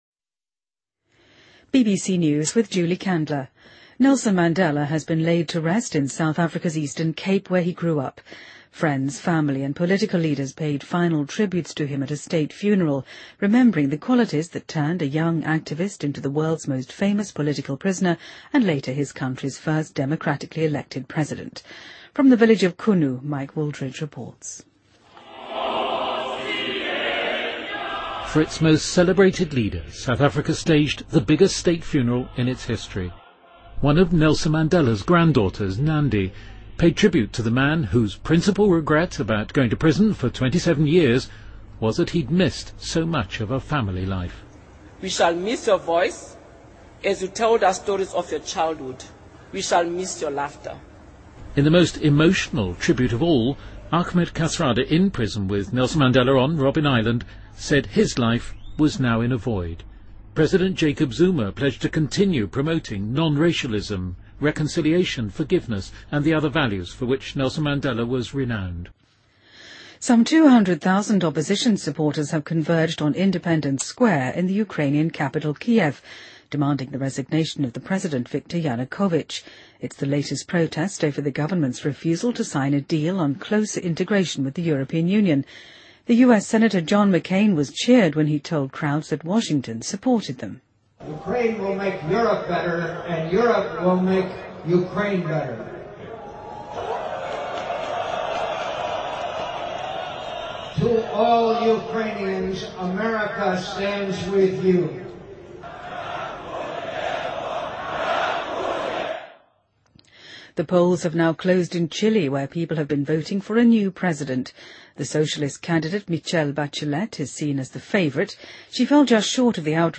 BBC news,德国总理安吉拉·默克尔任命该国首位女性国防部长乌苏拉·冯·德雷恩